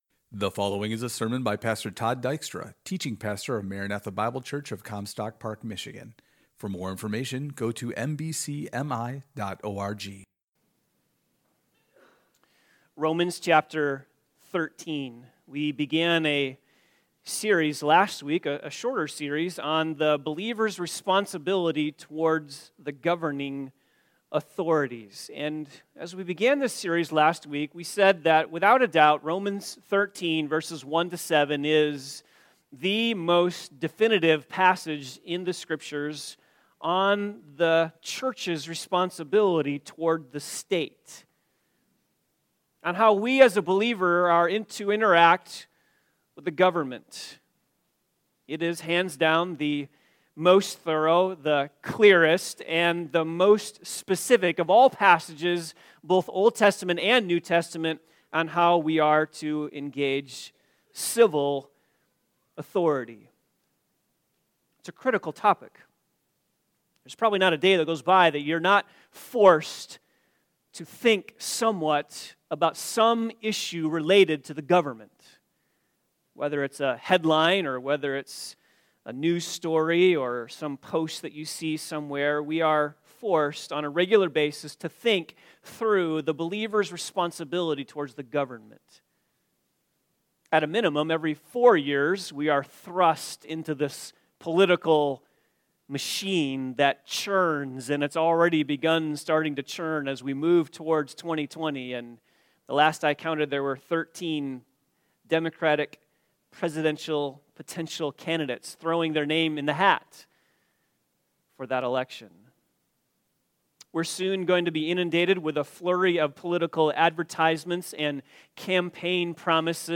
Believers must submit to governing authorities - Vs. 1 Today's sermon: 2. All Human Governments Are Established By God - vs. 1-2 Which is why we, as believers, must have an attitude of submission to government.